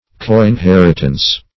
Coinheritance \Co`in*her"it*ance\, n. Joint inheritance.